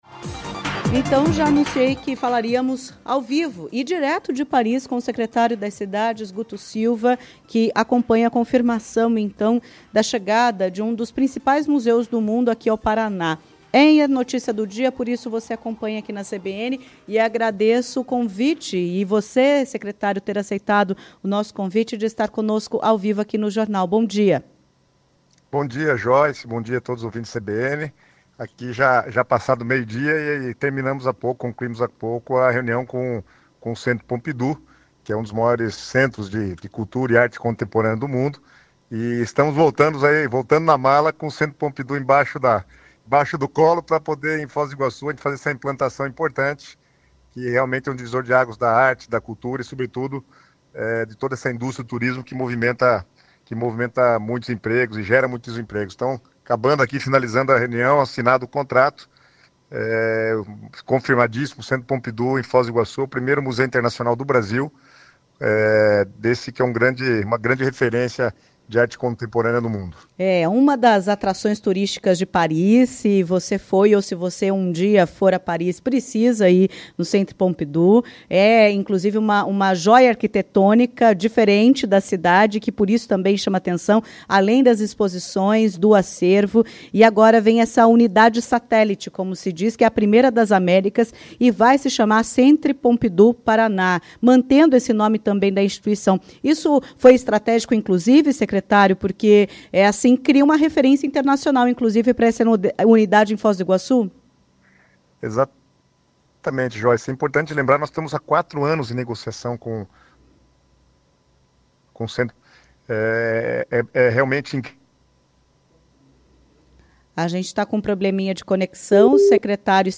Em entrevista à CBN Curitiba, o secretário das Cidades, Guto Silva, destacou a importância da unidade em Foz do Iguaçu.